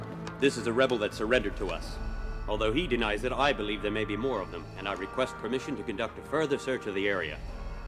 ―Commander Igar delivering Luke Skywalker to Darth Vader — (audio)